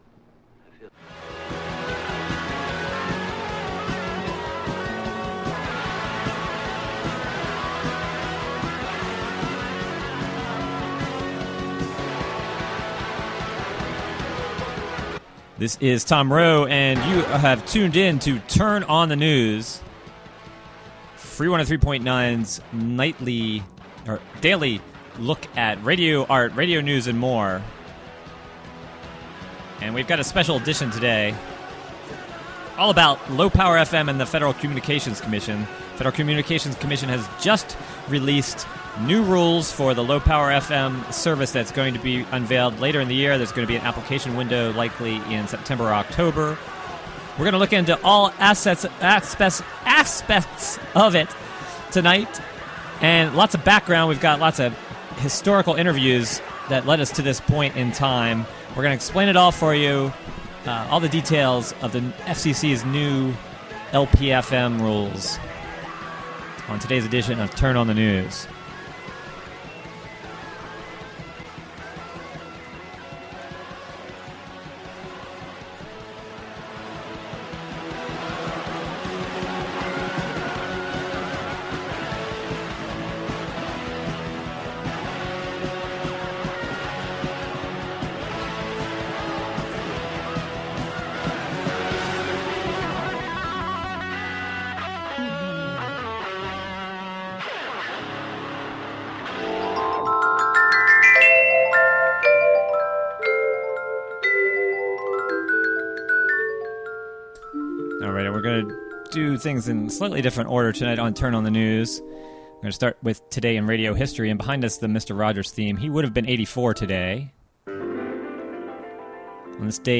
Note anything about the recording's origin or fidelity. Entire show dedicated to new Federal Communications Commission announcement about low-power FM. Includes historical recordings of fight with National Association of Broadcasters and Congress to create a licensing structure for low-power community radio stations to serve small population centers.